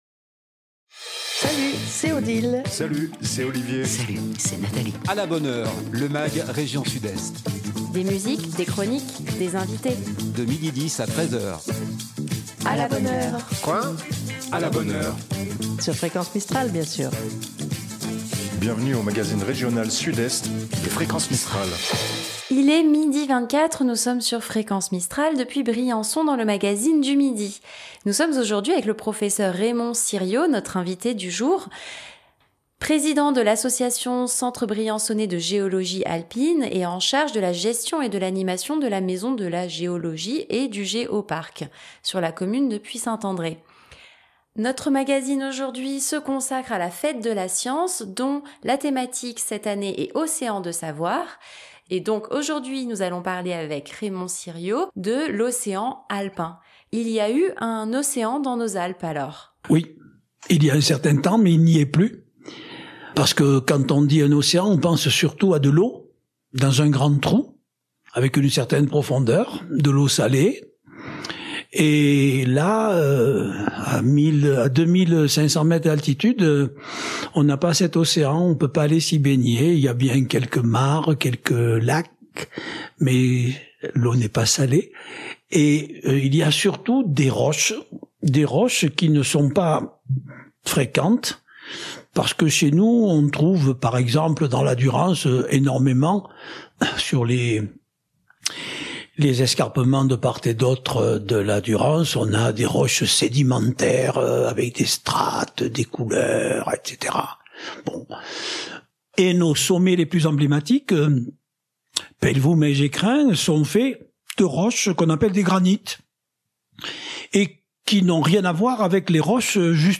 Une interview à réécouter juste ici : Magazine A la bonne heure